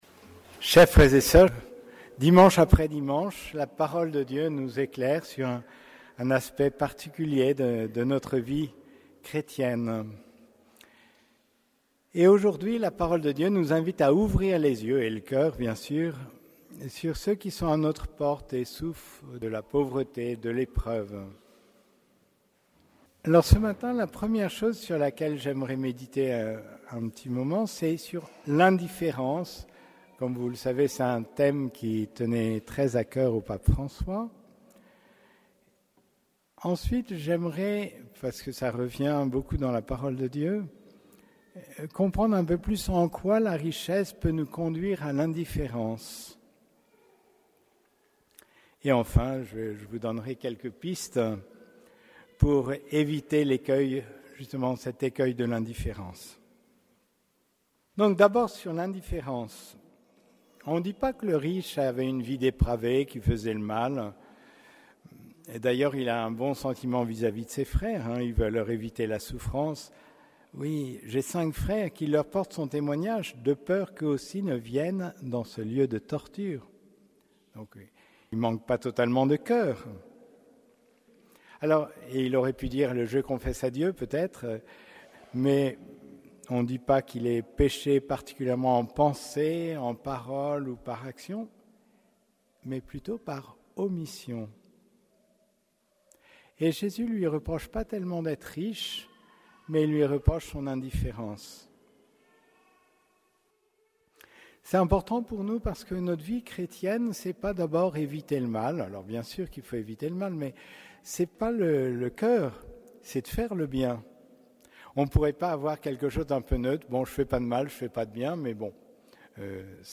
Homélie du 26e dimanche du Temps Ordinaire